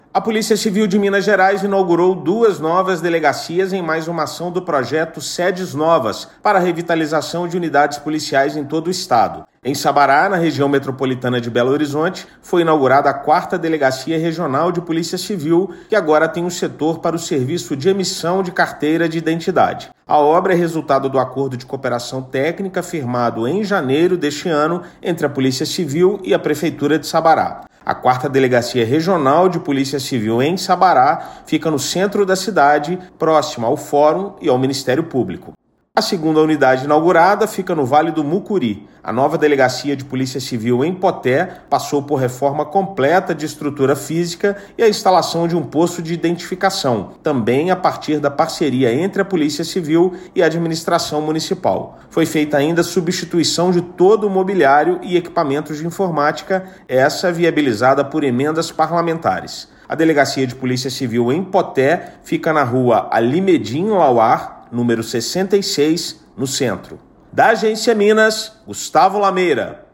Ação faz parte do projeto Sedes Novas, para a revitalização de unidades policiais em todo o estado. Ouça matéria de rádio.